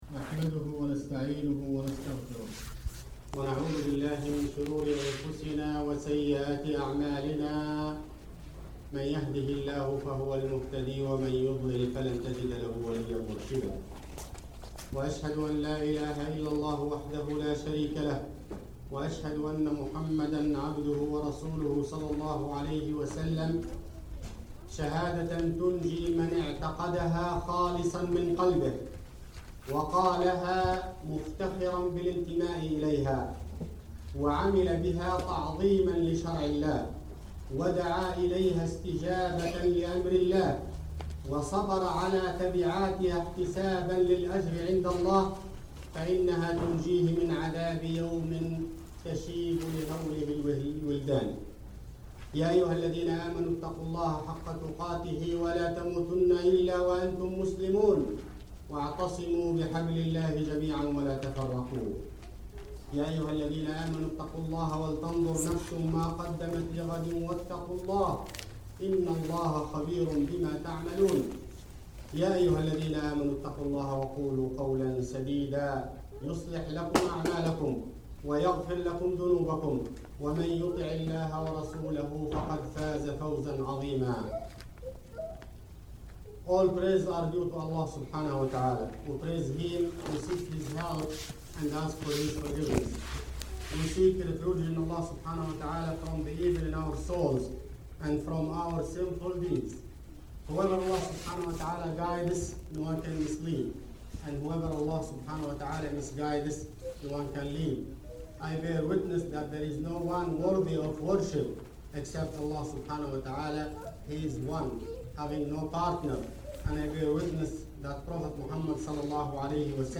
Had the joy this past Friday of attending a 23 minute khutbah with another 5 minutes for the salah. Joy because it was short, sweet, full of beneficial reminders, and stayed on point.